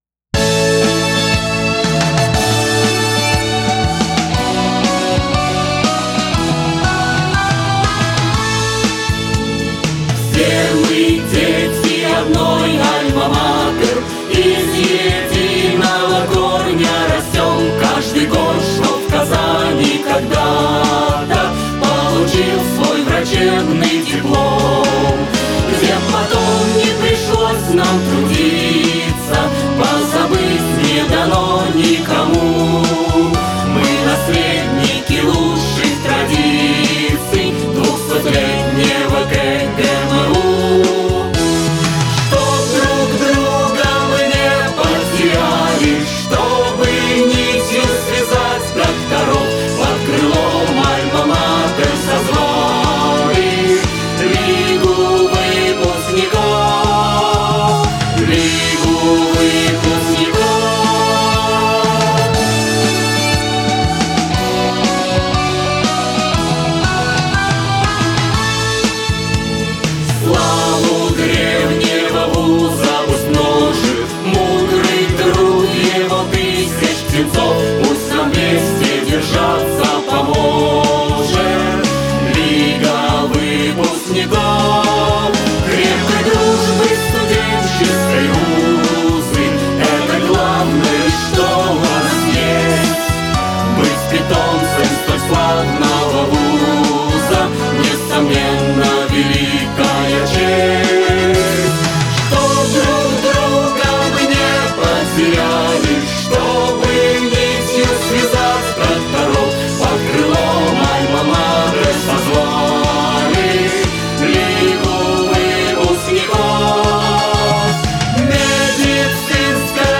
Спойте с нами